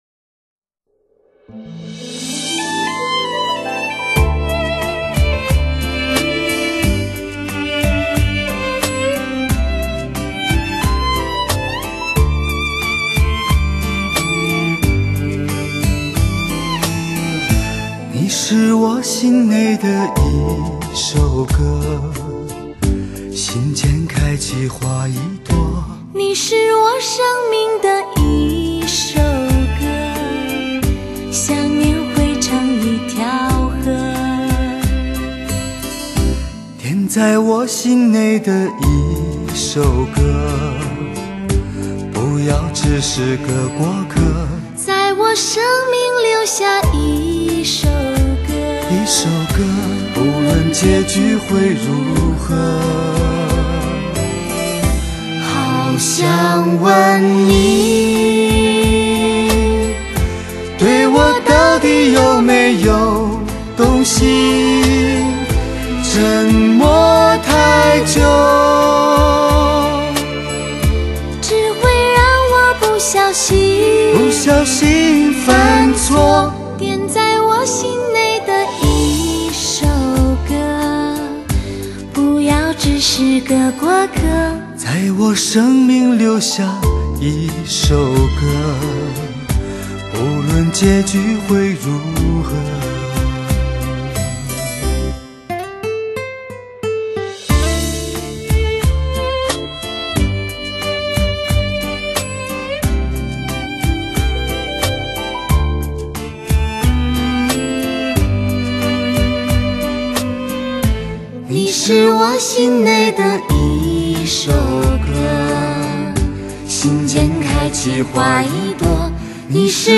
同样的演唱者磨练非一般的声质，非一般的音效捕捉音乐最细微的触点。
十三首贴近情人耳朵的经典表白，让大家再一次沉醉于爱情。